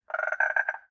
sounds / mob / frog / idle8.ogg